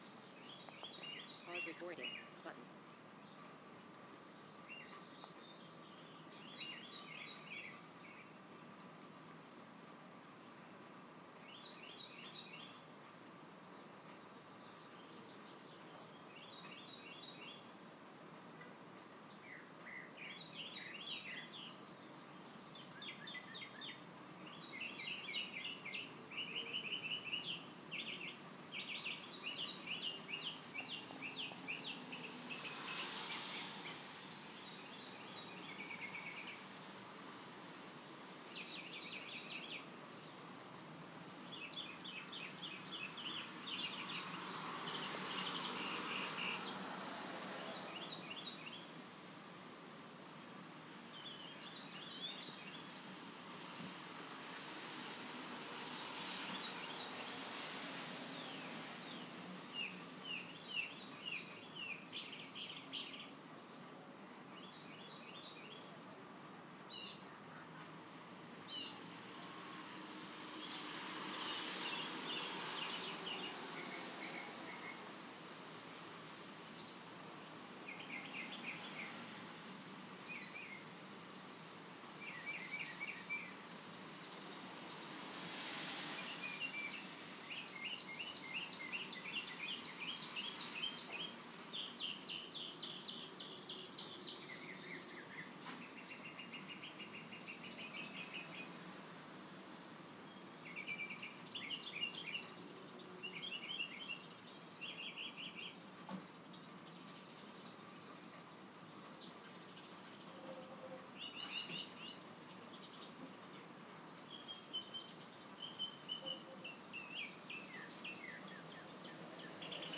Mockingbird on March 23, 2012